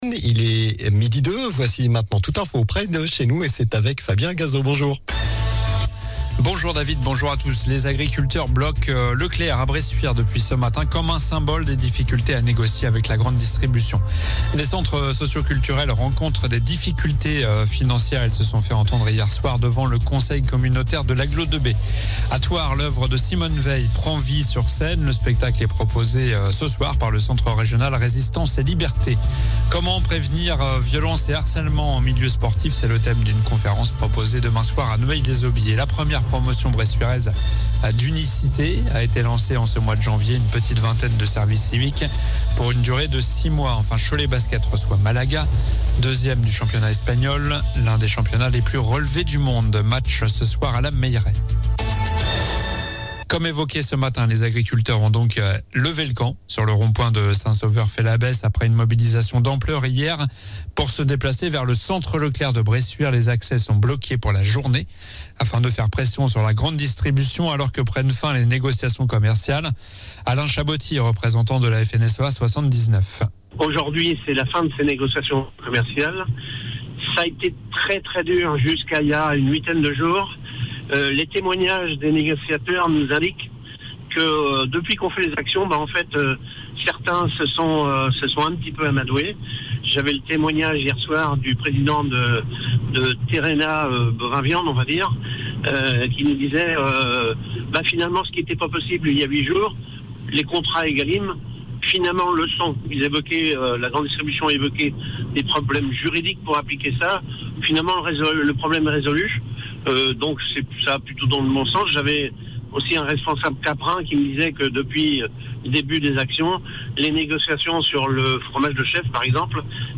Journal du mercredi 31 janvier (midi)